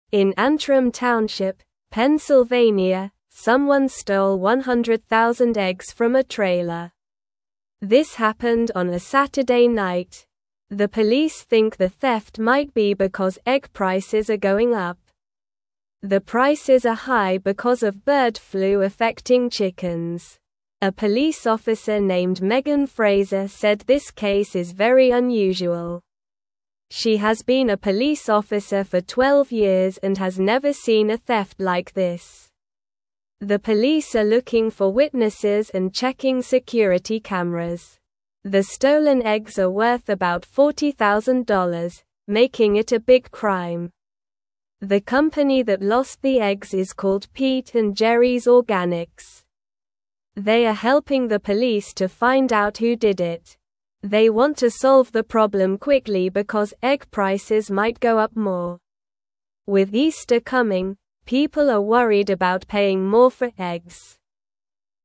Slow